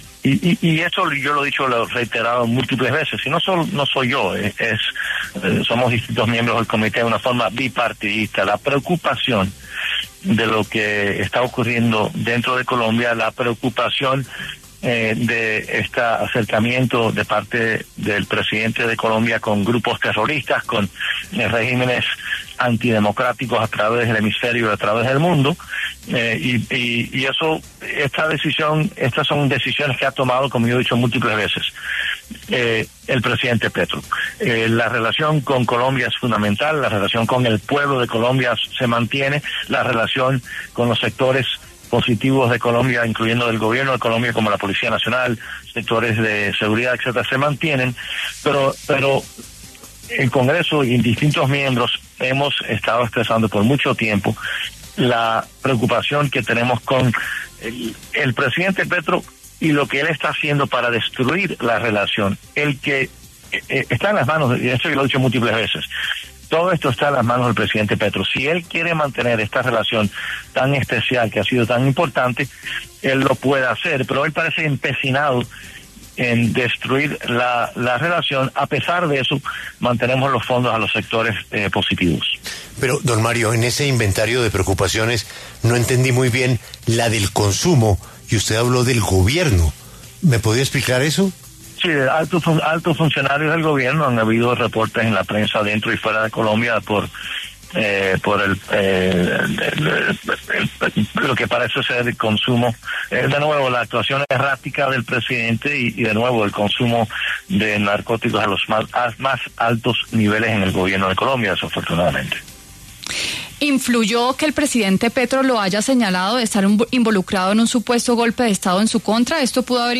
Mario Díaz-Balart, congresista de Estados Unidos, habló en La W, con Julio Sánchez Cristo, sobre la preocupación que, según dice, le ha generado al legislativo norteamericano el actuar del presidente colombiano, Gustavo Petro.